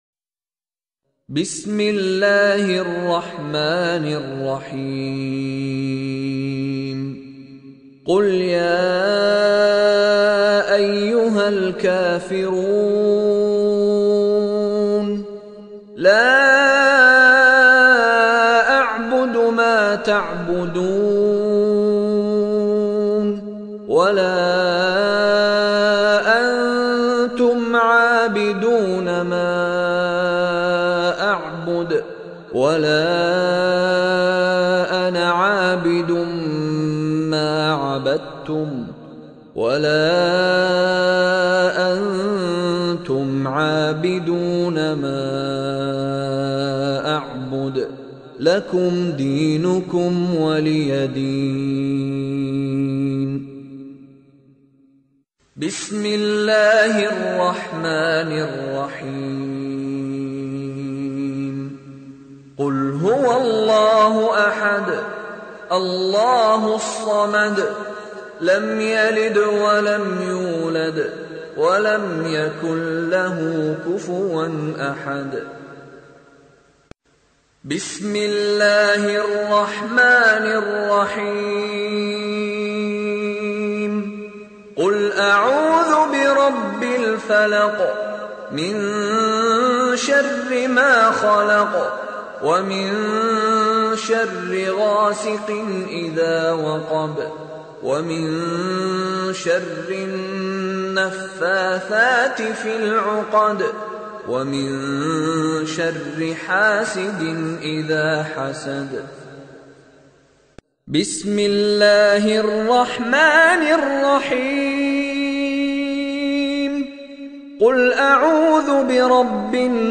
Listen or play online mp3 Qul Shareef in the voice of Sheikh Mishary Rashid Alafasy.